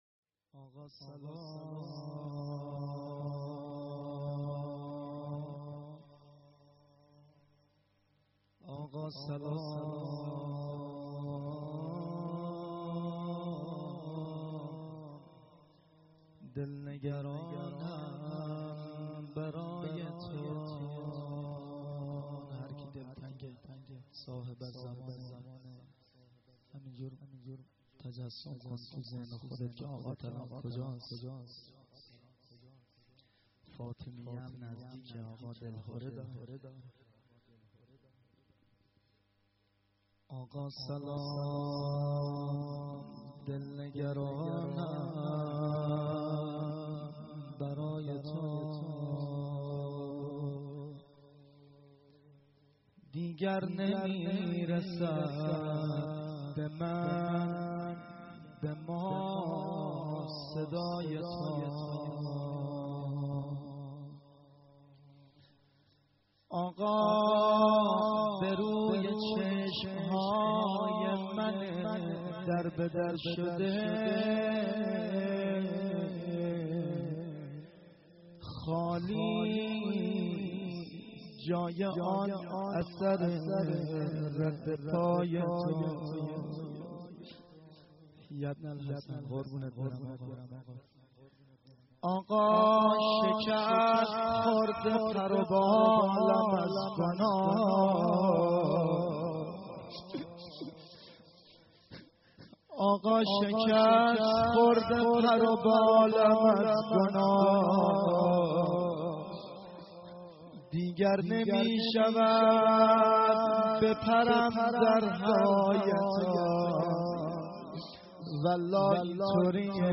مراسم هفتگی 94/10/27